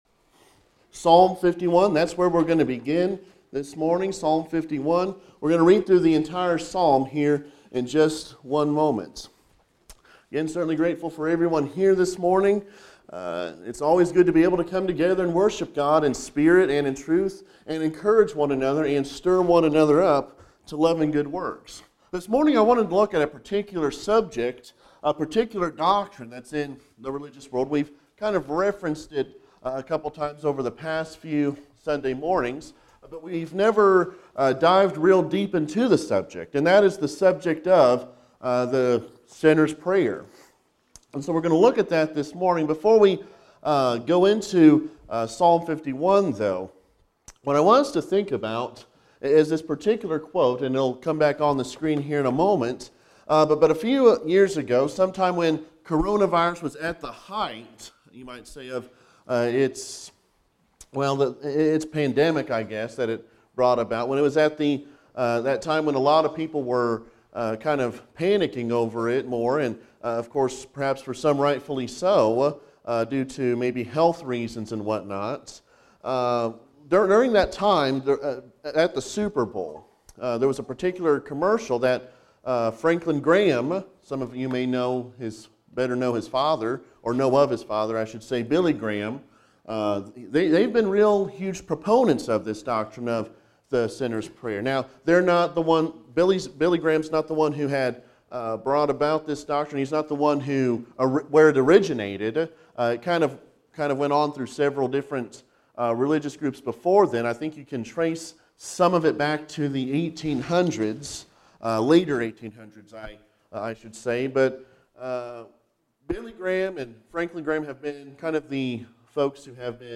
Sunday Preaching-AM
Service: Sunday AM